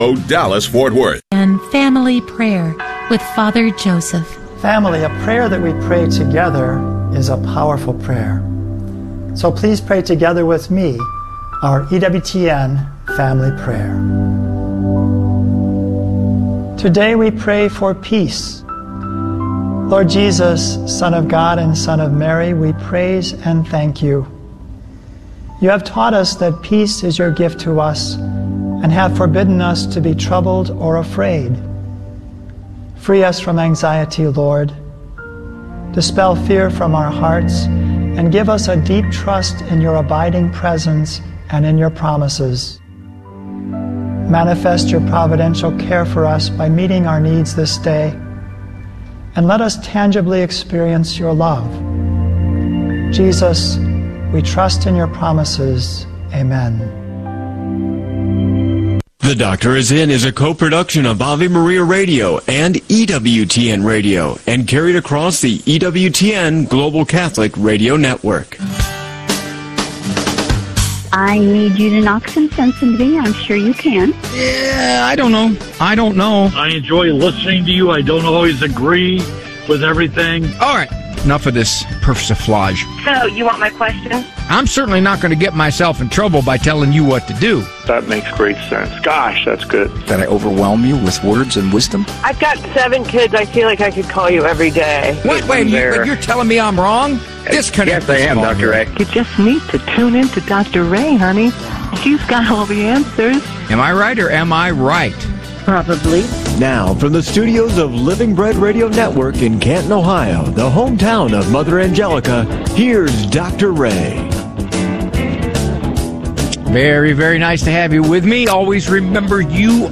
The Good News - Catholic Radio North Texas Style The Good News - Monday January 26, 2026 Recorded on Monday January 26, 2026 Share this episode on: The Good News - Catholic Radio North Texas Style One hour of solid, Catholic conversation for your Monday afternoon. Focuses on issues pertinent to North Texas Catholics.